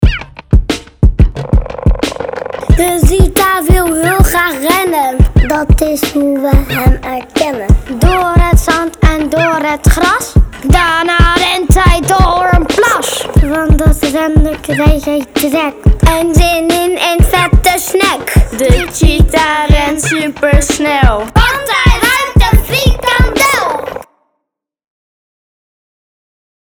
Zondag 22 februari vond het Kletskoppen Festival plaats in Bibliotheek Mariënburg, en we kijken terug op een onvergetelijke dag.
Ook maakten kinderen
een toffe rap